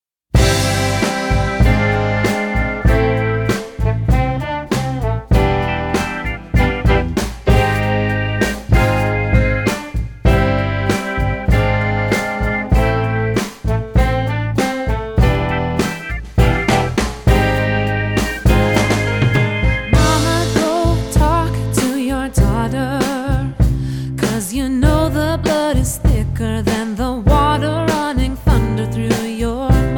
piano
saxophone
trumpet
clarinet
flugel horn
trombone